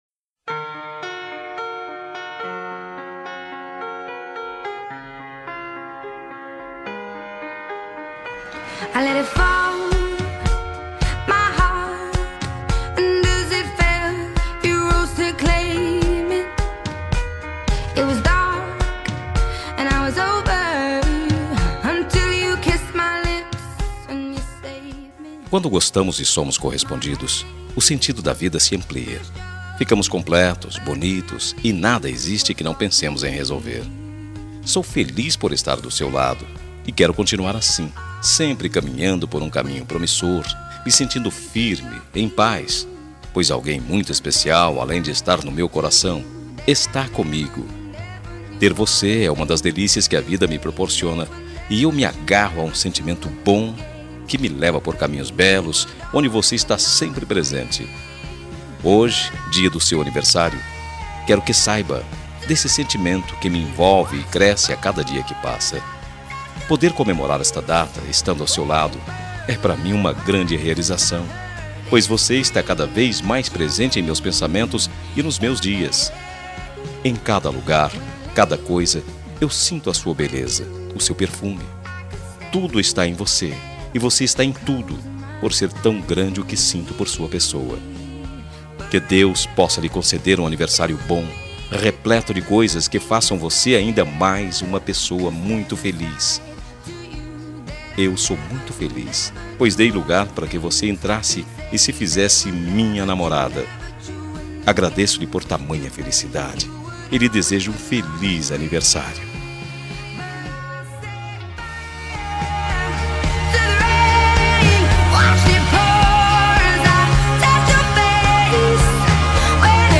Telemensagem Aniversário Romântico – Voz Masculina – Cód: 09808
aniversario-romantico-suave-02-m-adele-set-fire-to-the-rain.mp3